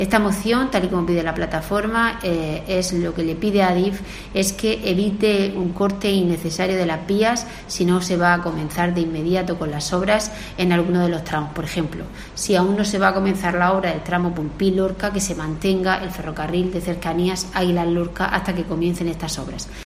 María del Carmen Moreno, alcaldesa de Águilas